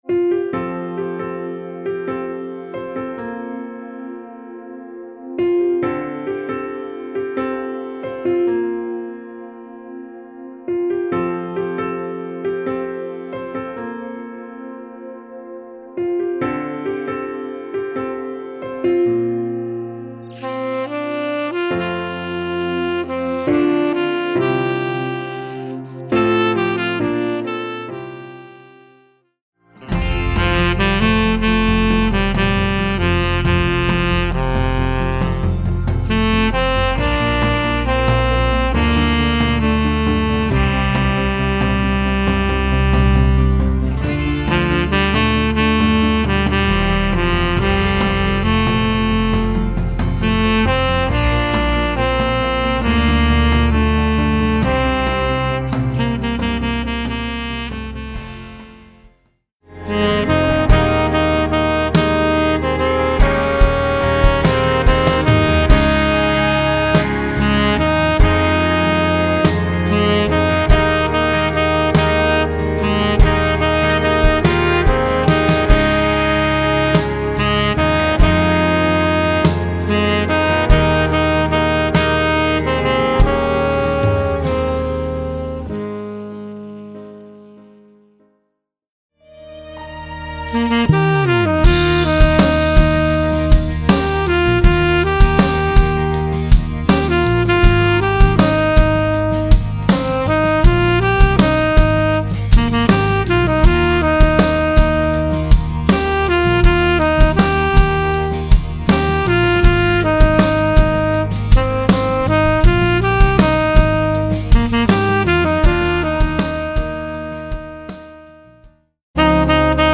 Voicing: Tenor Sax